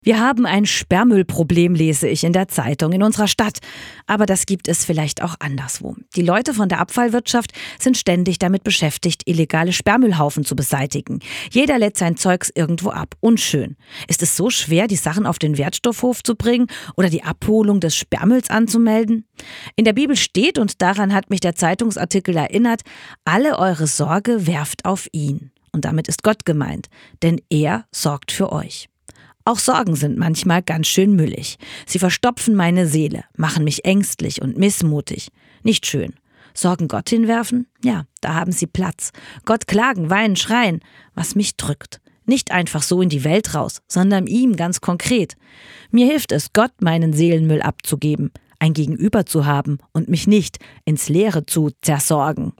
Startseite > andacht > Sperrmüll